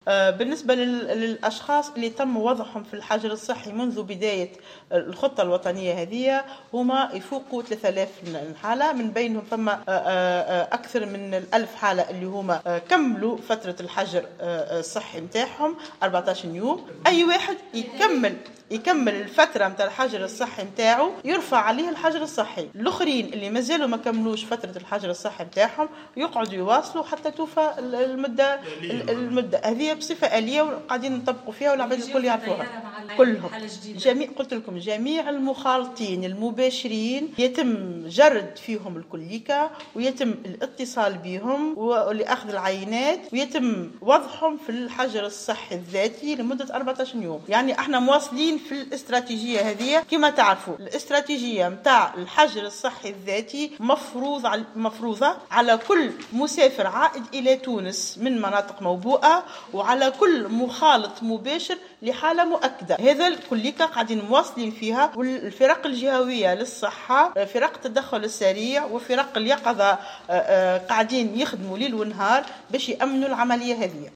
أكدت المديرة العامة للأمراض الجديدة والمستجدة بوزارة الصحة نصاف بن علية في تصريح لمراسل الجوهرة "اف ام" أن عدد الاشخاص الذين تم وضعهم في الحجر الصحي بلغ 3000 شخص من بينهم 1000 شخص أنهوا فترة الحجر المحددة ب14 يوم .